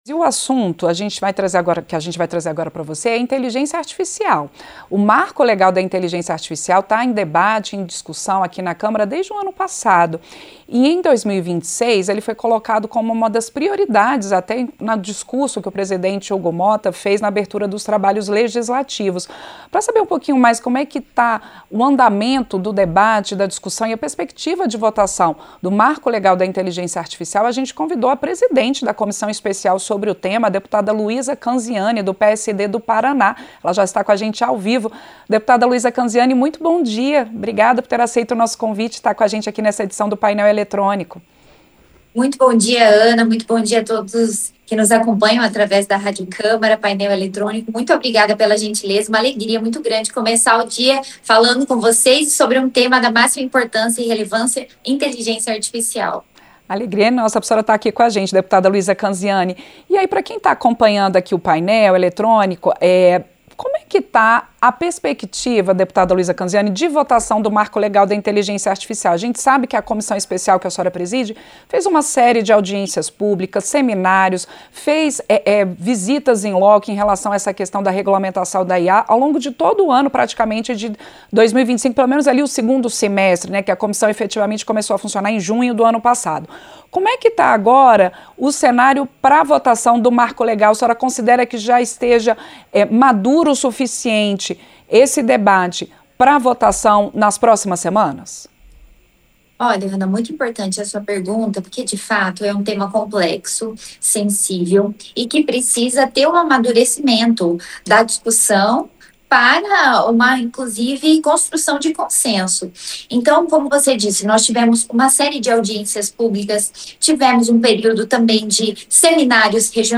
Entrevista - Dep. Luísa Canziani (PSD/PR)